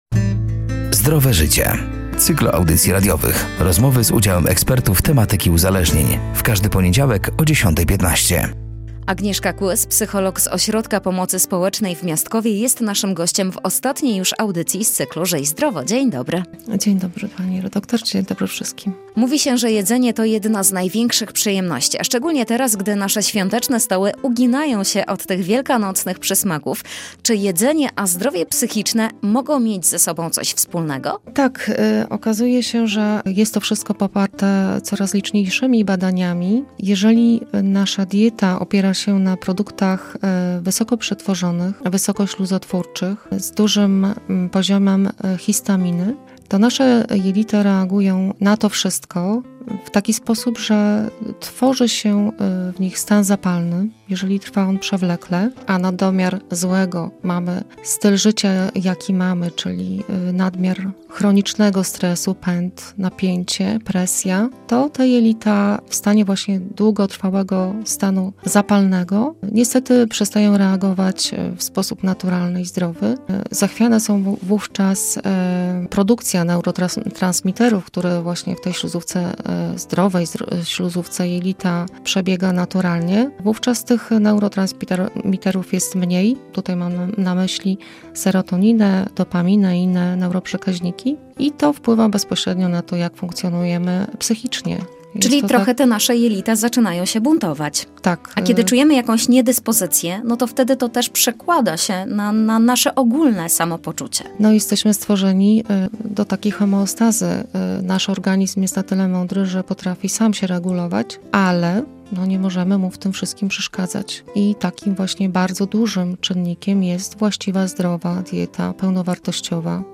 „Zdrowe Życie” to cykl audycji radiowych. Rozmowy z udziałem ekspertów tematyki uzależnień.